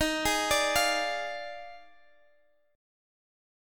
Listen to D#M7sus2sus4 strummed